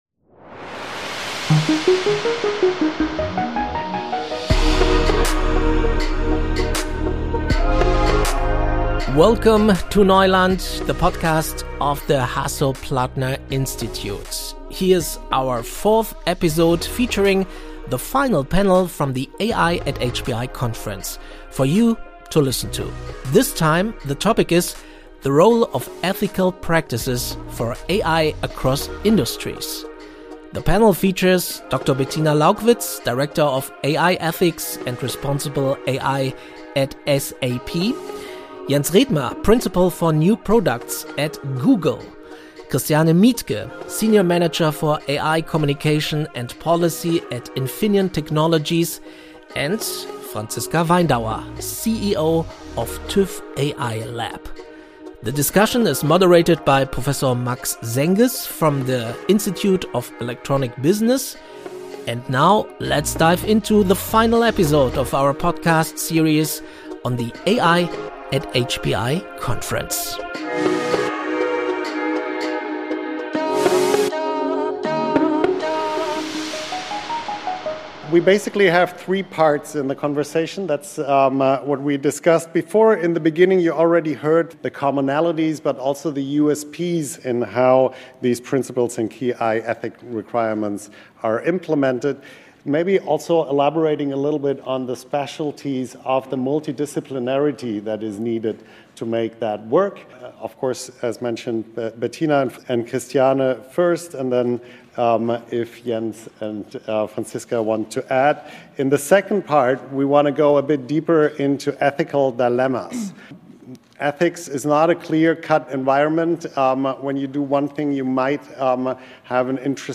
Expert:innen des Hasso-Plattner-Instituts sprechen verständlich über digitale Entwicklungen und Trends, über Chancen und Risiken der Digitalisierung. Jede Sendung widmet sich einem gesellschaftlich relevanten Thema: Von der Macht der Künstlichen Intelligenz über die Blockchain bis zu Hetze in den sozialen Medien.